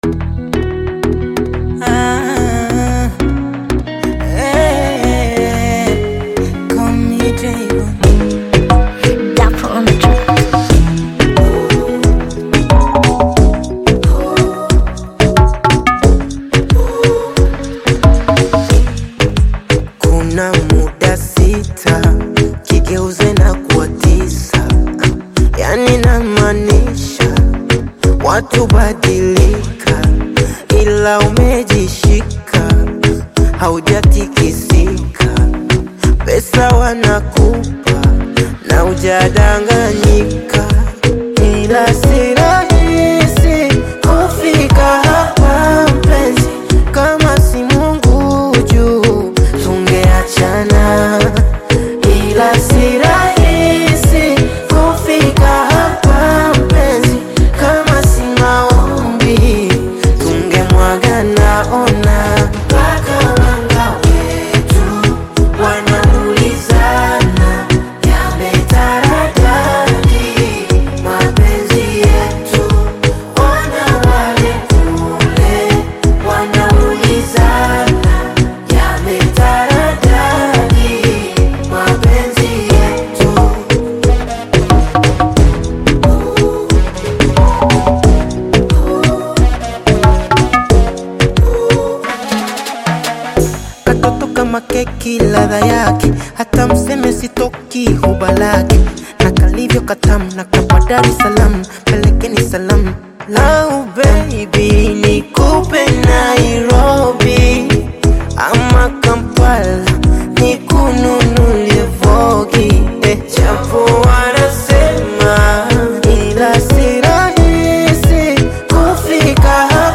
Bongo Flava music track
Tanzanian Bongo Flava artist, singer and songwriter